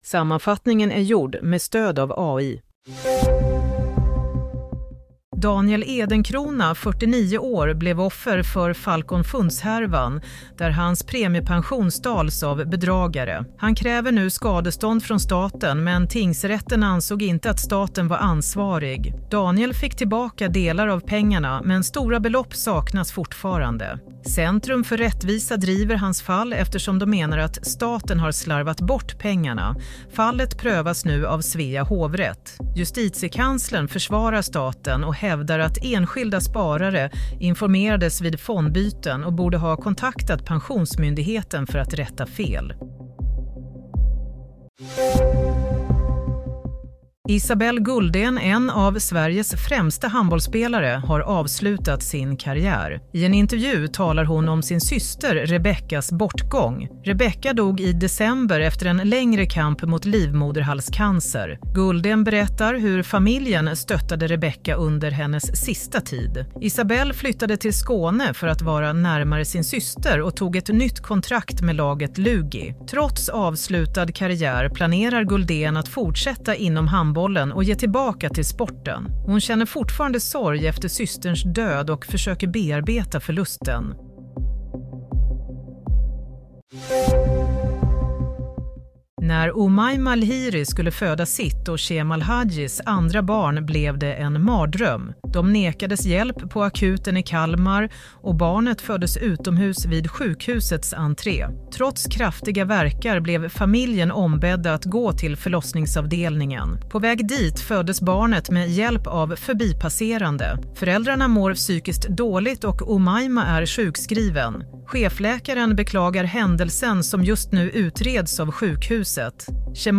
Nyhetssammanfattning - 17 mars 07:00